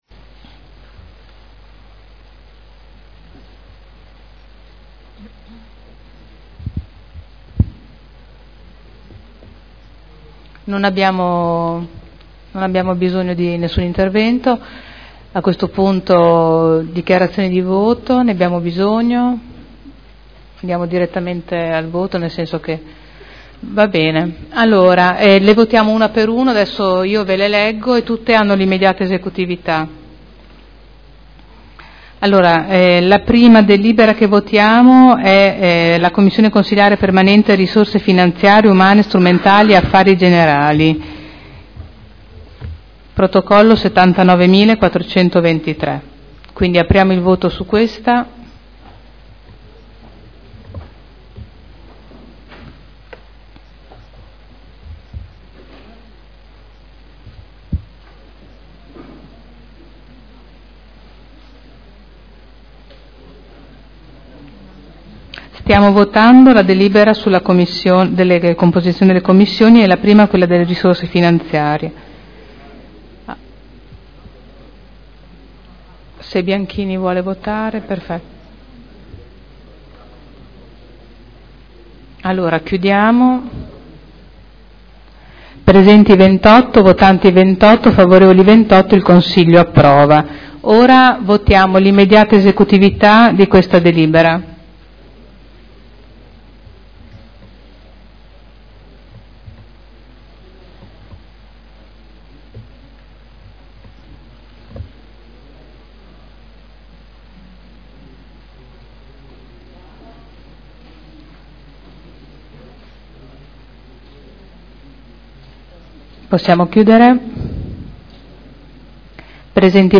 Seduta del 16/07/2012 Il Presidente Caterina Liotti mette ai voti le 5 delibere sulla nuova composizione delle Commissioni. Approvate tutte e 5.